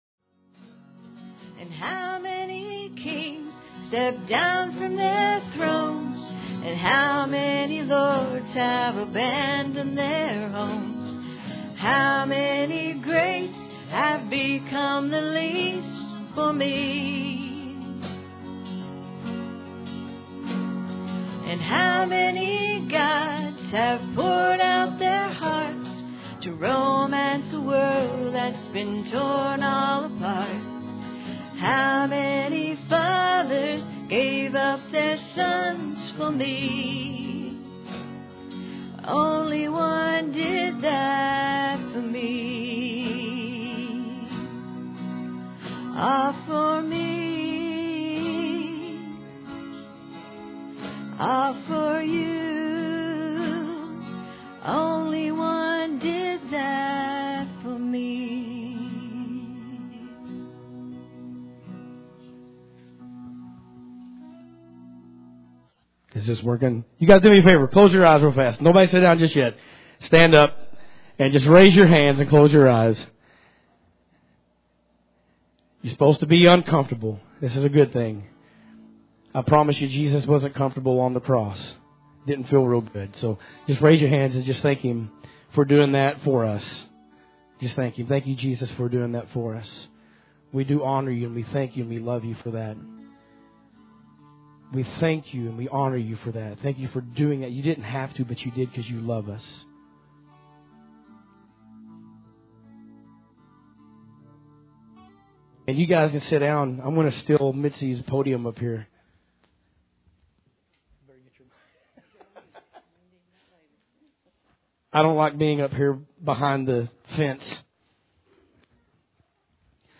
Philippians 4:4-9 Service Type: Sunday Morning Audio Version Below